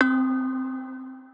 Bell - Tropics.wav